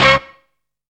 TOWN HIT.wav